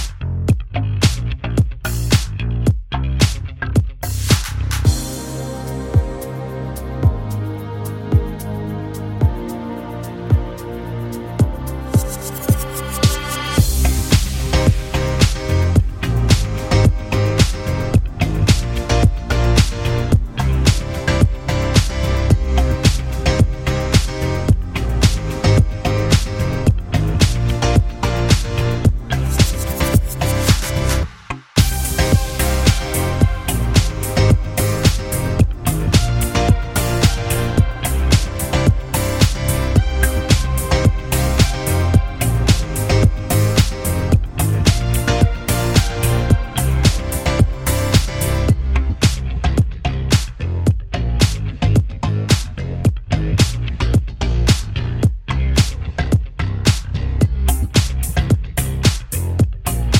Minus Main Guitar Pop (2010s) 3:20 Buy £1.50